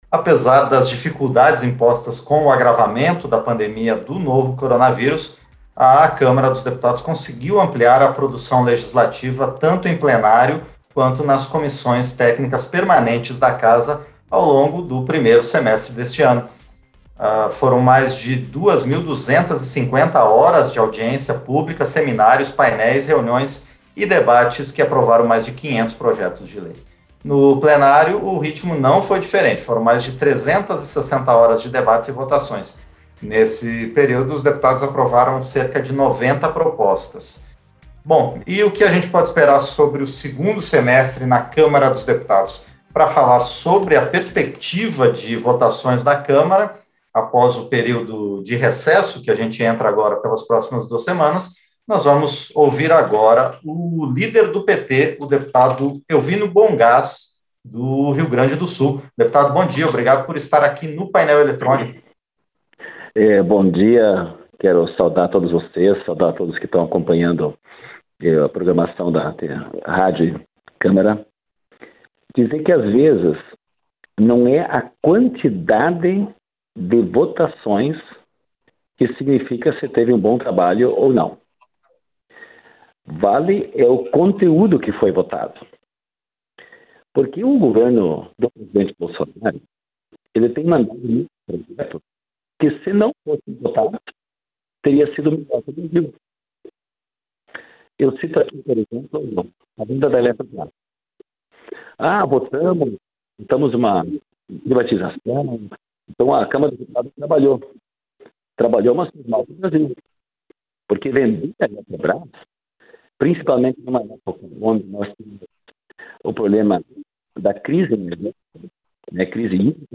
ENTREVISTA - dep. Bohn Gass (PT-RS)